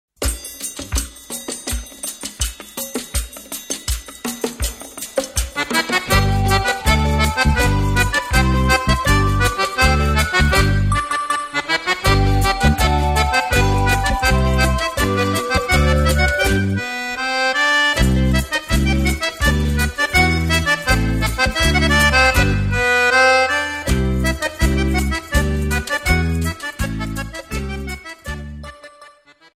Bayon